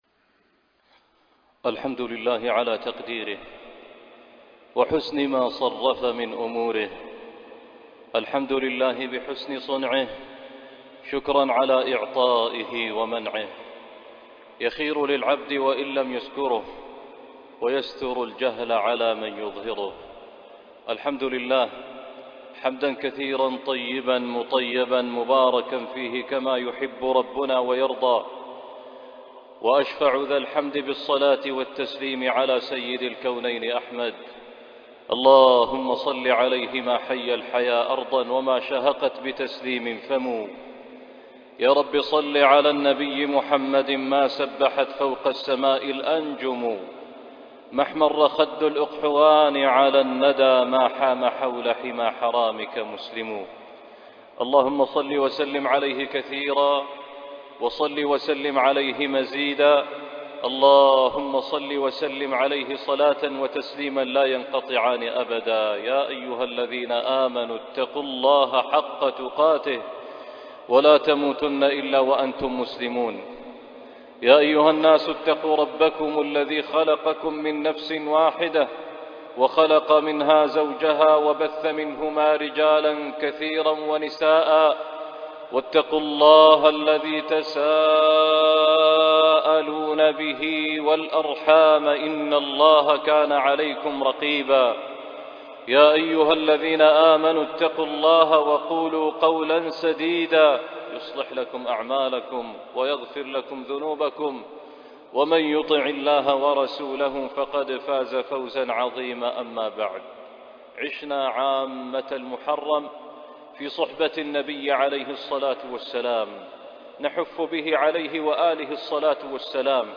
《 خارطة الشعور 》خطبة الجمعة 10 صفر 1443